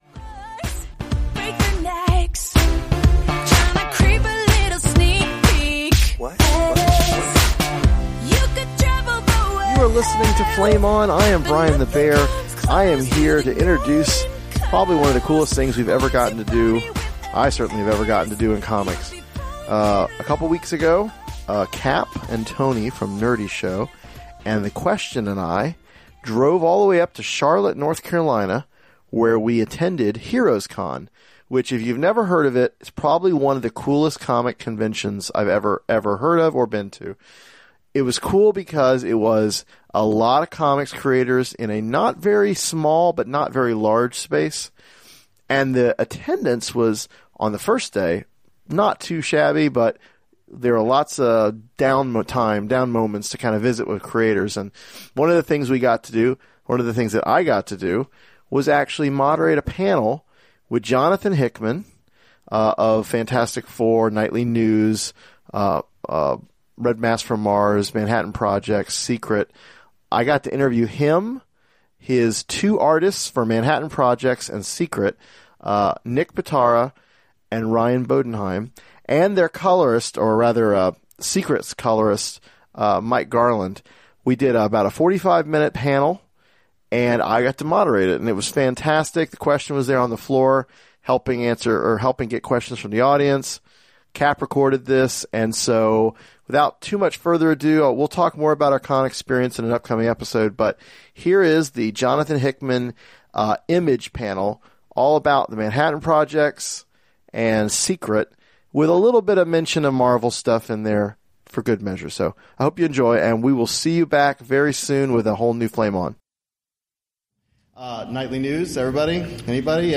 Jonathan Hickman Panel at HeroesCon 2012
Also, apologies in advance for the intro music, but see if you can spot the connection!